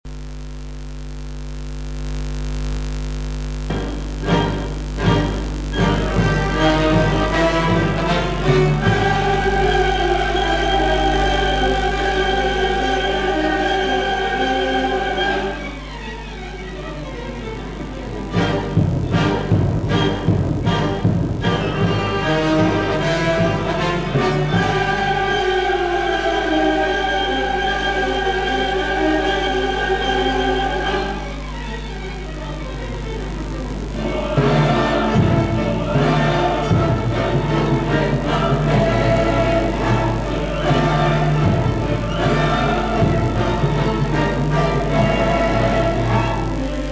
（雑音有り）